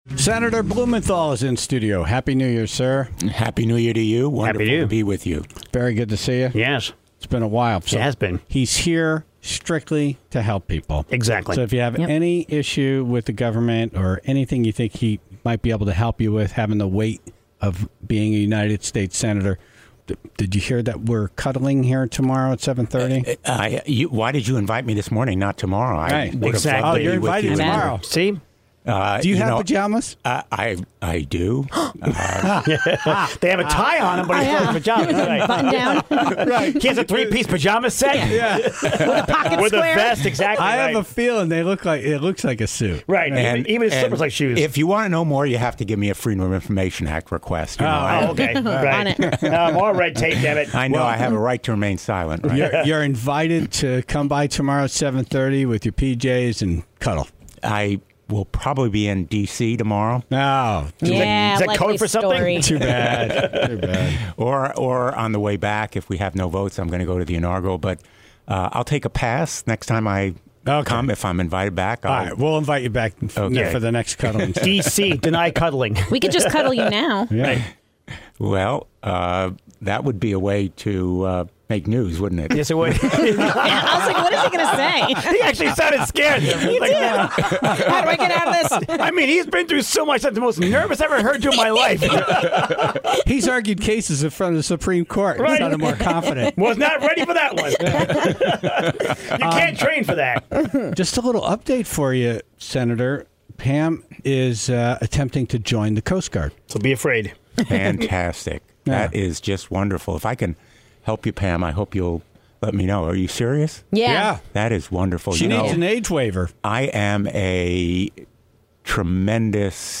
Senator Blumenthal in Studio